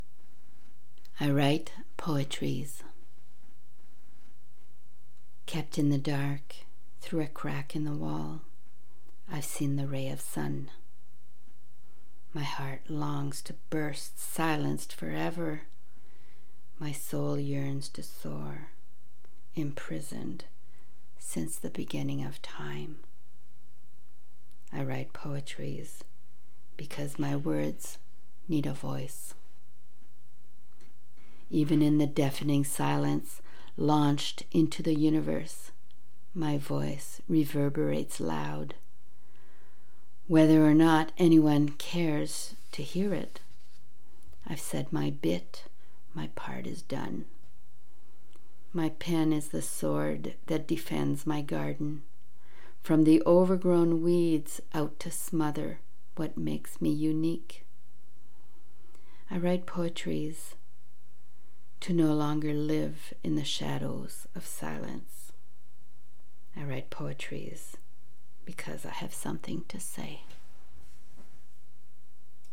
Read on air by invitation  ~  April 7, 2021  'LATE NIGHT POETS'